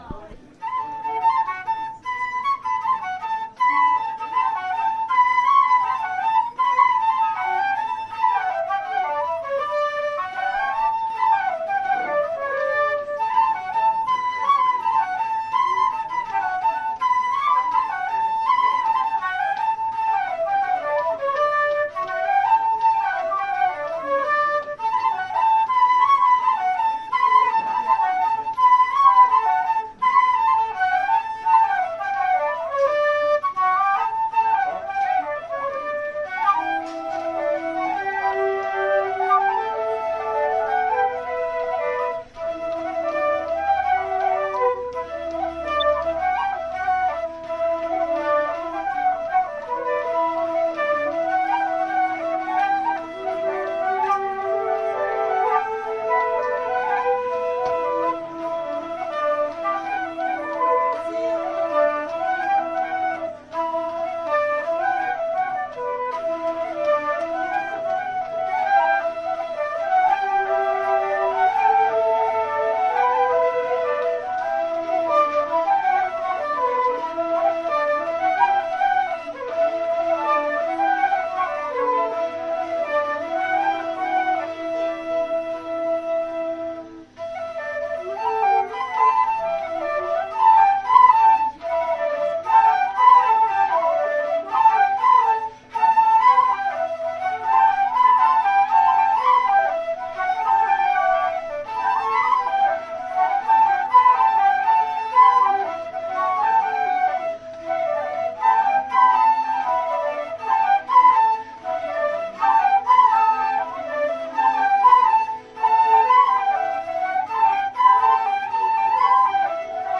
06_loudeac_flute.mp3